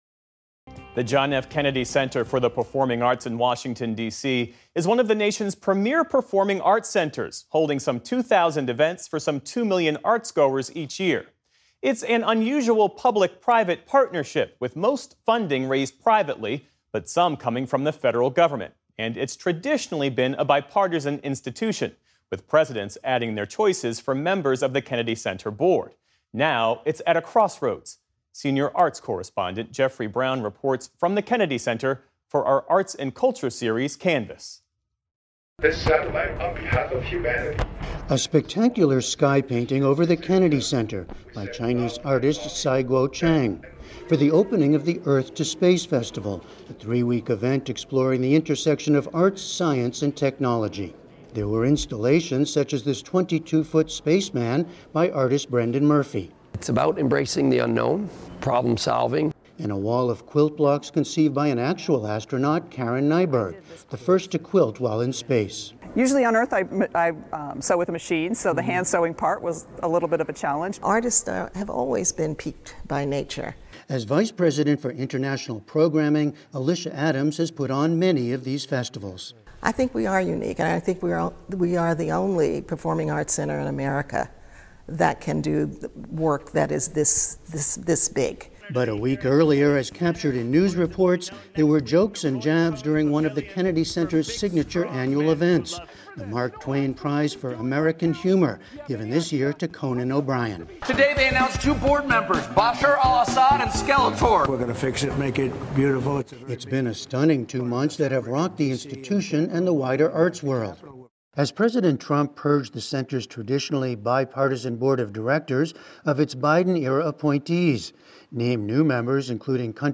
News, Daily News
The center has traditionally been a bipartisan institution but it’s now at a crossroads. Jeffrey Brown reports for our arts and culture series, CANVAS.